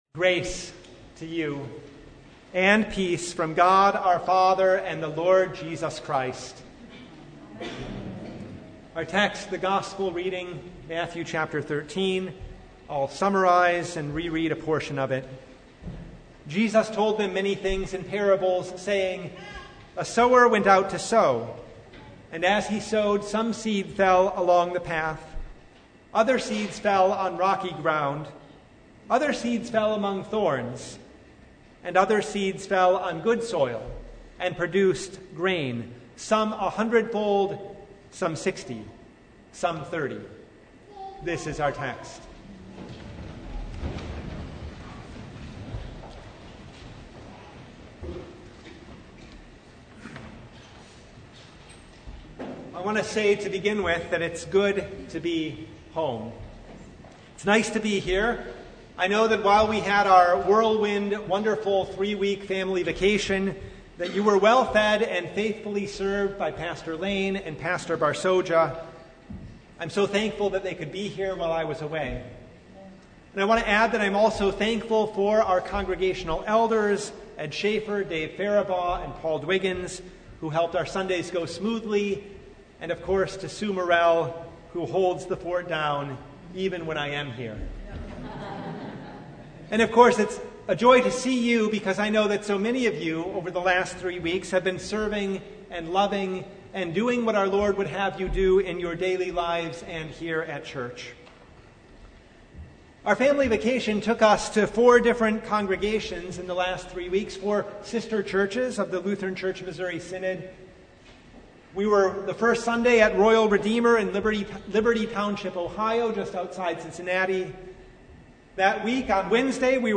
18-23 Service Type: Sunday A sower went out to sow .